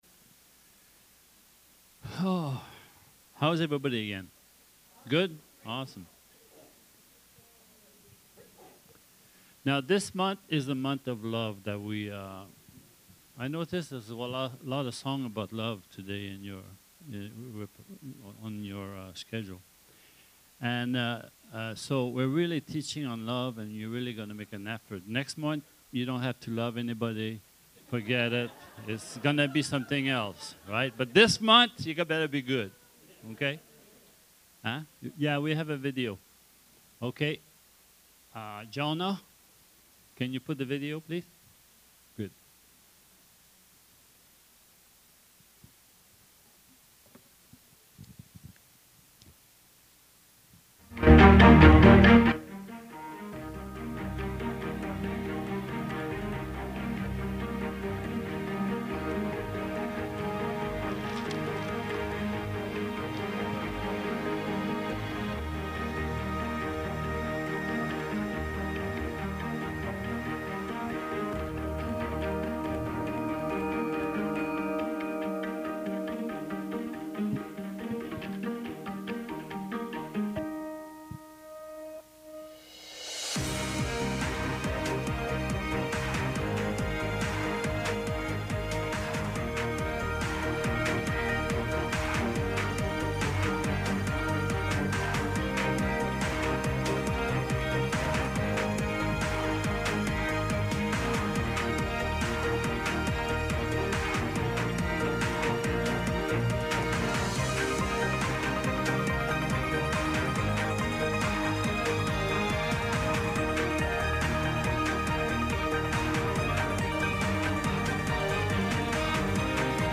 Audio Sermons - At The Crossroads Church